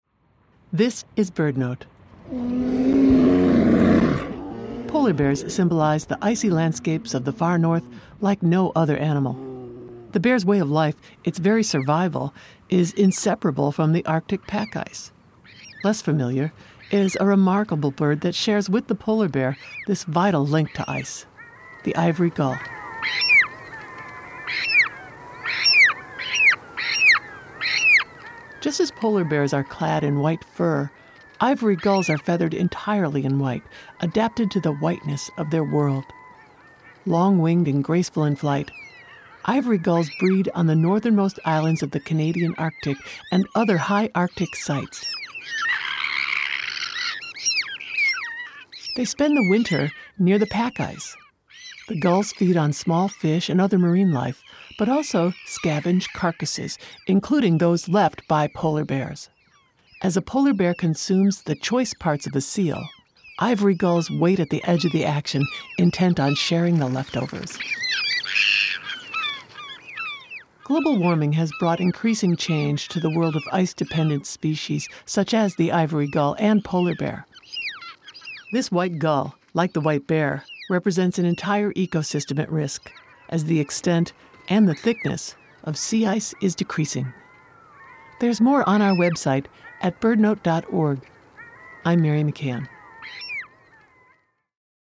BirdNote is sponsored locally by Chirp Nature Center and airs live every day at 4 p.m. on KBHR 93.3 FM.